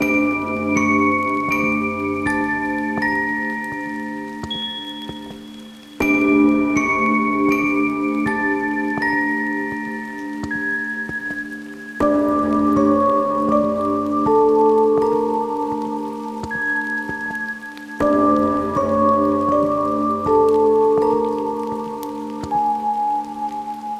Loop